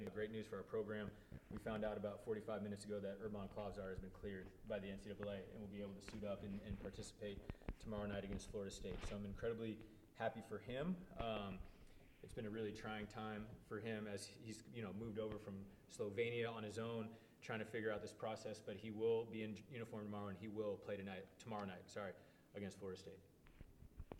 in his news conference